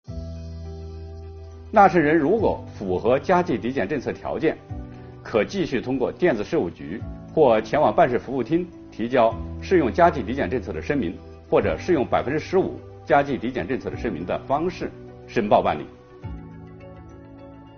本期课程由国家税务总局货物和劳务税司副司长刘运毛担任主讲人，对2022年服务业领域困难行业纾困发展有关增值税政策进行详细讲解，方便广大纳税人更好地理解和享受政策。今天我们来学习：纳税人如果符合加计抵减政策条件，如何提交适用加计抵减政策申请？